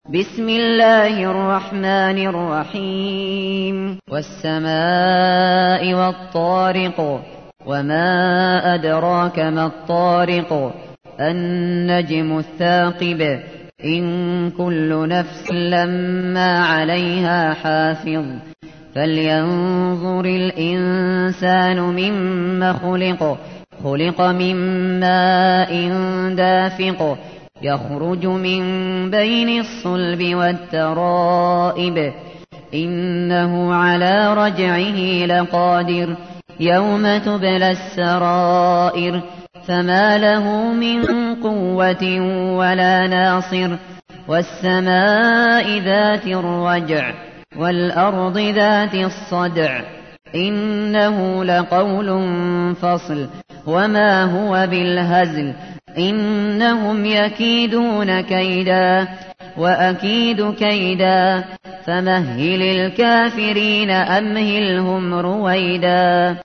تحميل : 86. سورة الطارق / القارئ الشاطري / القرآن الكريم / موقع يا حسين